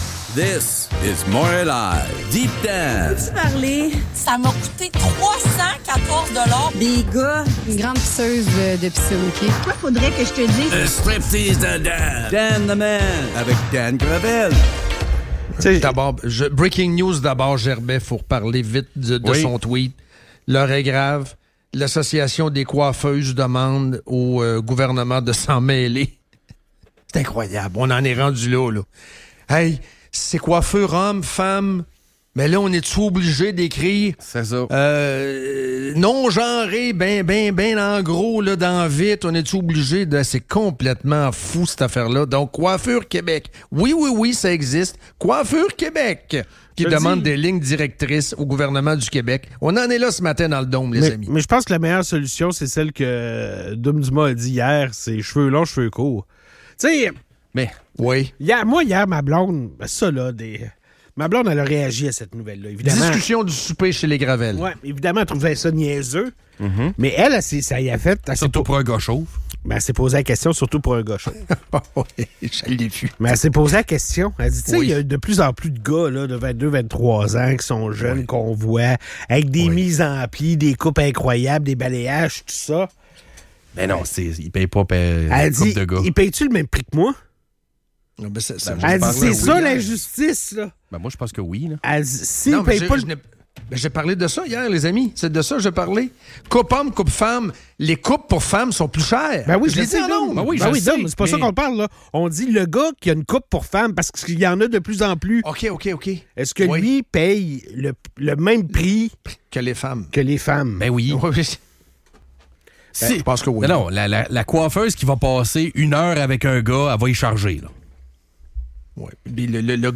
La question de l'équité des prix en coiffure entre hommes et femmes est au cœur des discussions, avec des témoignages sur les différences de coût et le temps passé dans un salon. Les animateurs explorent également les stéréotypes associés aux coupes de cheveux et l'impact des attentes culturelles sur les tarifs.